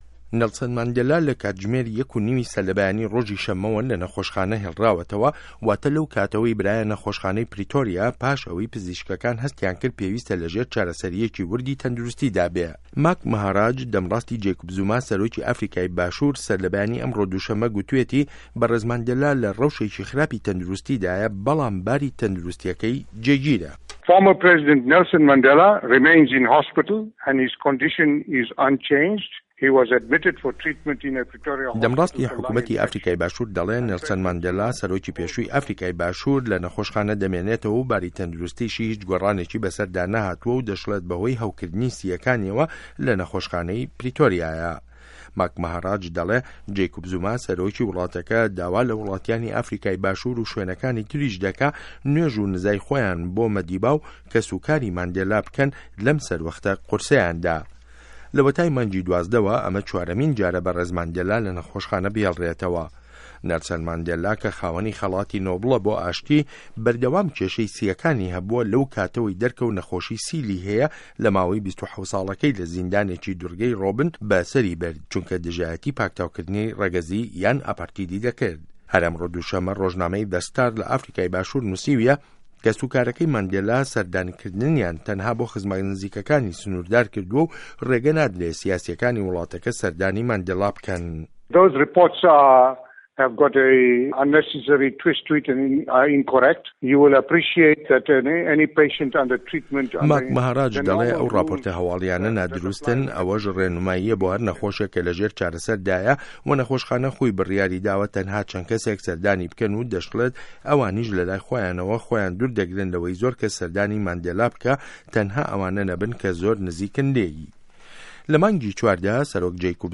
ڕاپـۆرتی ماندێلا